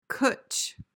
PRONUNCIATION:
(kuch [u as in push or bush])